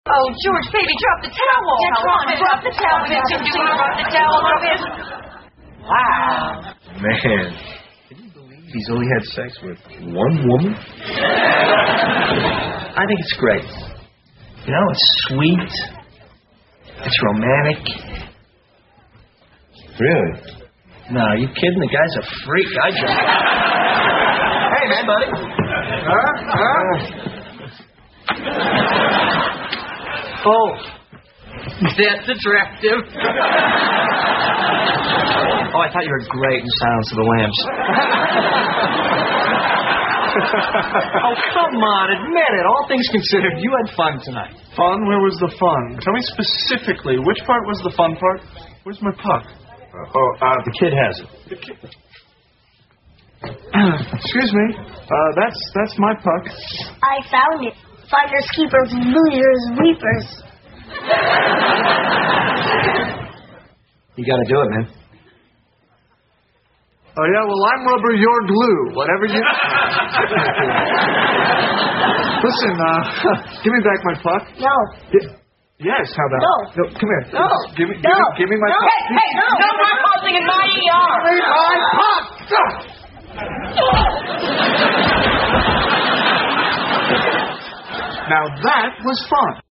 在线英语听力室老友记精校版第1季 第46期:克林顿亲信助手(14)的听力文件下载, 《老友记精校版》是美国乃至全世界最受欢迎的情景喜剧，一共拍摄了10季，以其幽默的对白和与现实生活的贴近吸引了无数的观众，精校版栏目搭配高音质音频与同步双语字幕，是练习提升英语听力水平，积累英语知识的好帮手。